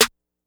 Snare (16).wav